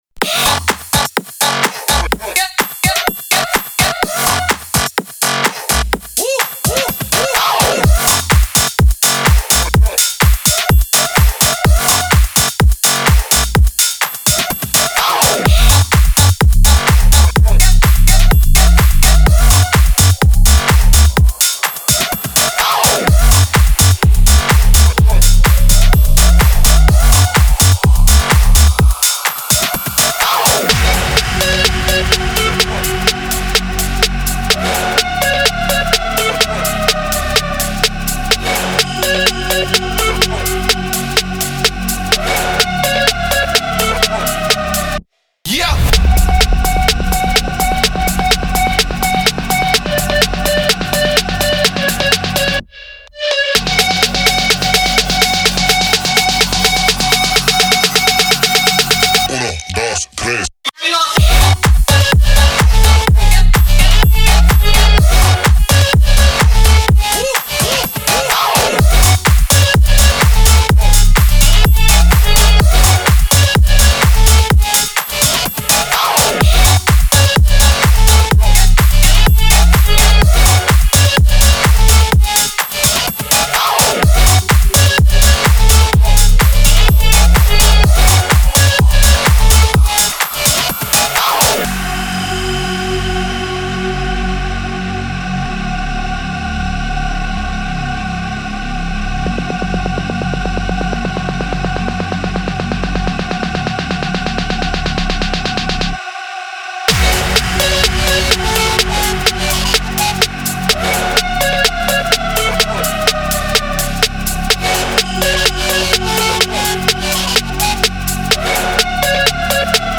Прошу критики EDM трека